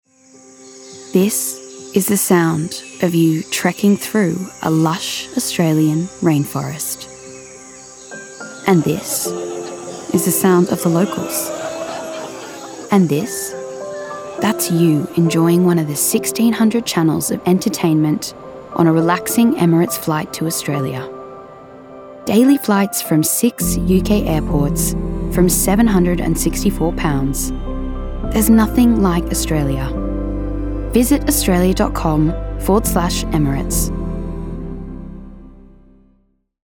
Female
English (Australian)
Yng Adult (18-29), Adult (30-50)
Radio Commercials
Australian Commercial Example